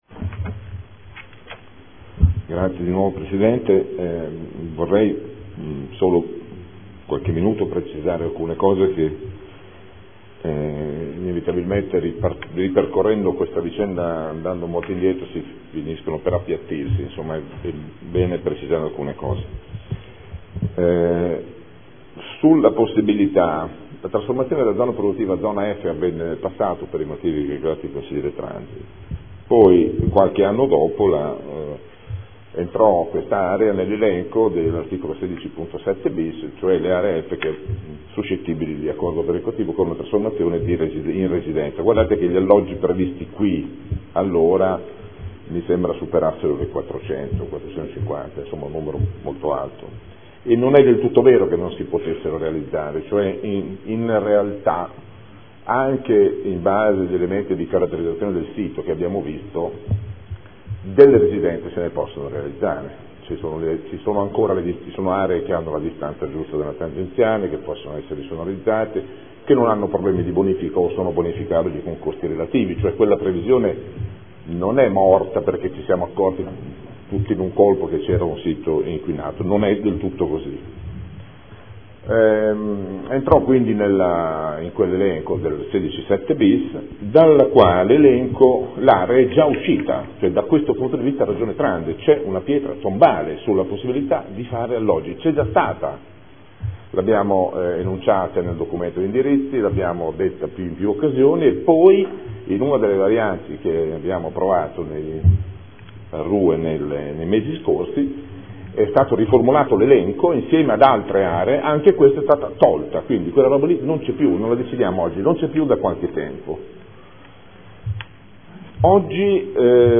Seduta del 20/03/2014 Replica. Variante al Piano Strutturale Comunale (PSC) – Area ubicata tra Tangenziale, Strada Ponte Alto e Stradello Anesino – Zona elementare 2050 – Area 01